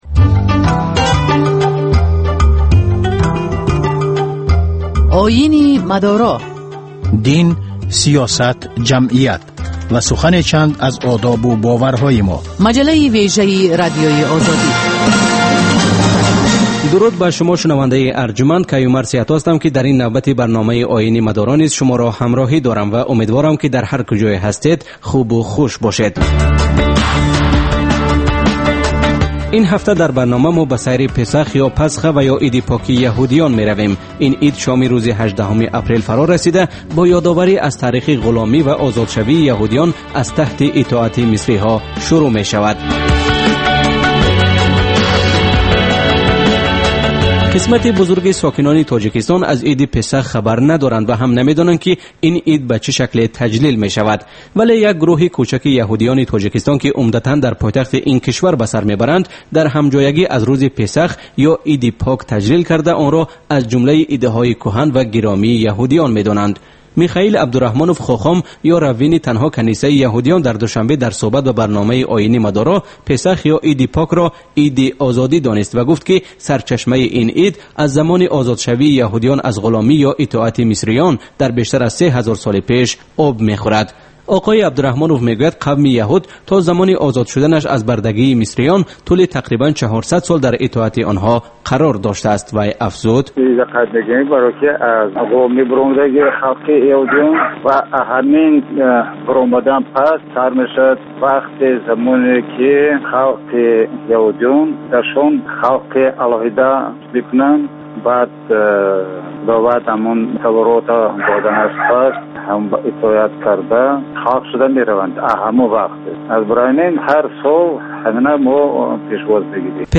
Гузориш, мусоҳиба, сӯҳбатҳои мизи гирд дар бораи муносибати давлат ва дин.